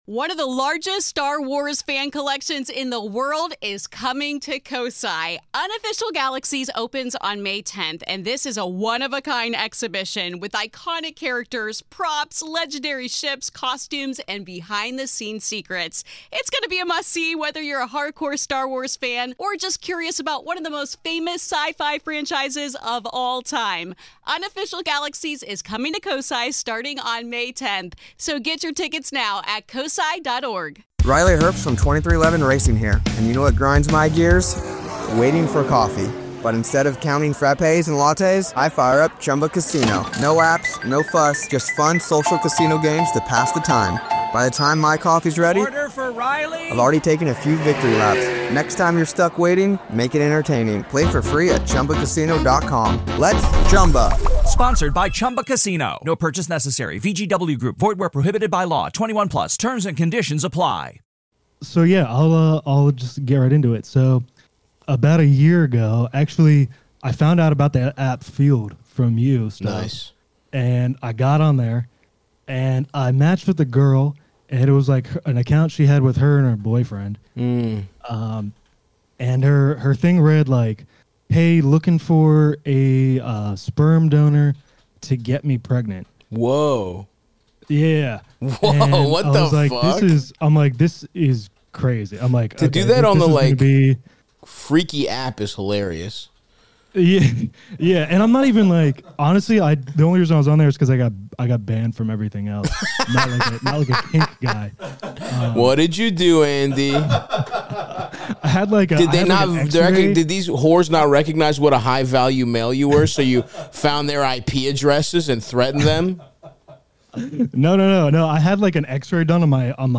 Patreon preview.